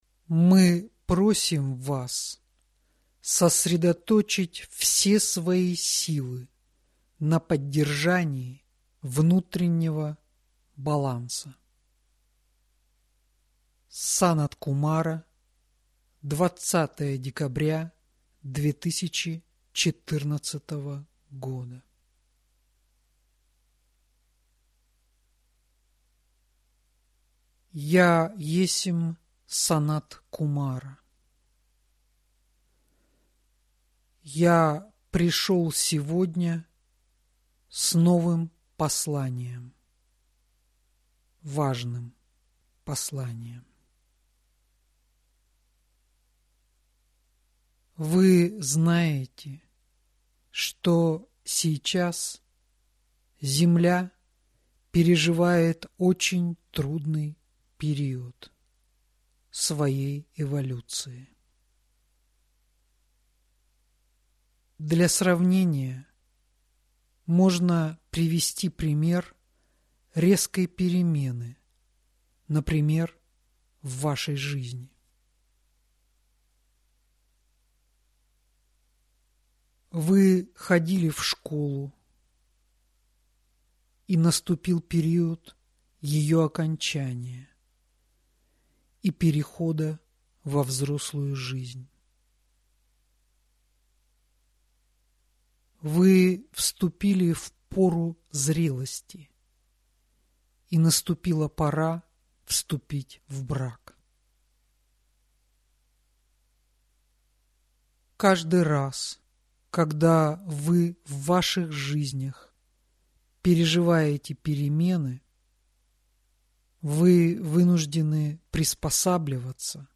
Аудиокнига Слово мудрости 21 | Библиотека аудиокниг